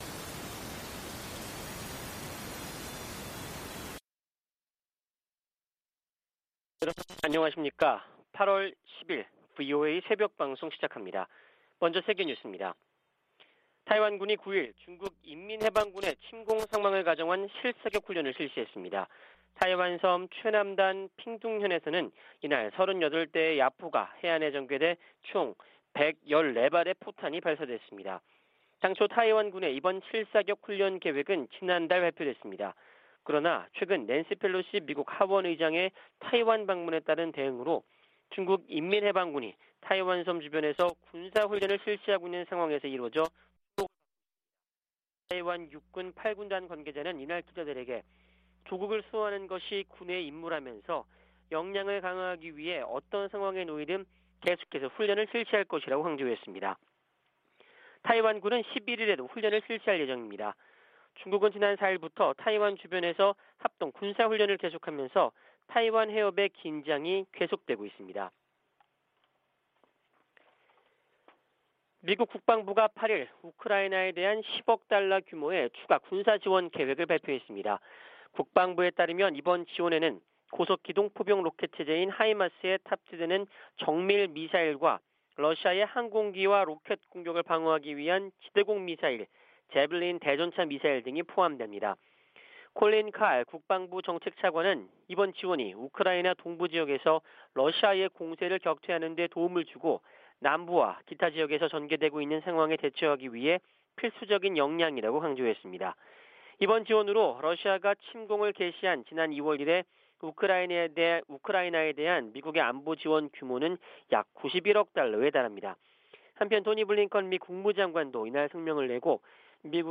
VOA 한국어 '출발 뉴스 쇼', 2022년 8월 10일 방송입니다. 미국 정부가 북한 해킹조직 라자루스가 탈취한 가상화폐의 세탁을 도운 믹서 업체를 제재했습니다. 국무부는 우크라이나 친러시아 세력의 독립인정을 강력히 규탄하며 북한 노동자 파견은 대북 제재 위반이라는 점을 분명히 했습니다. 미 하원의원들이 베트남전쟁에 미군과 함께 참전했던 미국 내 한인들에게 의료 혜택을 제공하는 입법을 촉구했습니다.